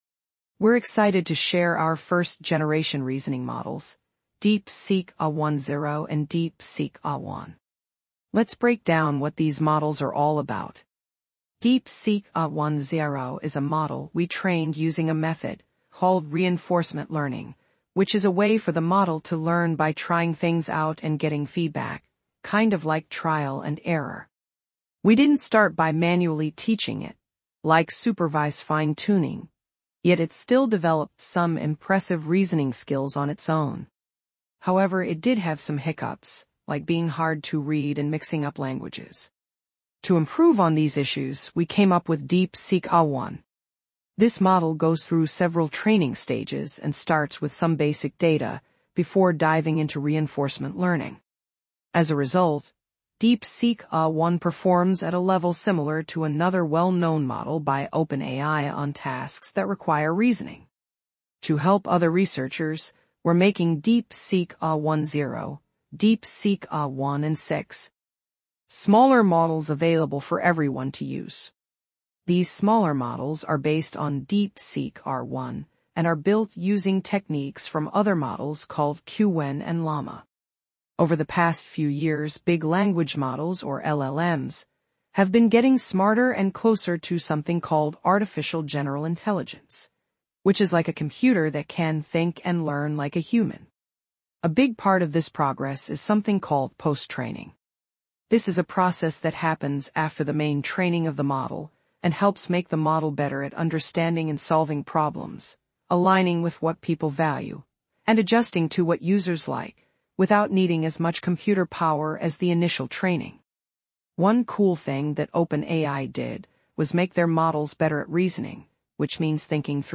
Abideify is a powerful document processing pipeline that transforms complex PDF documents into simplified Markdown text and optional podcast-style audio.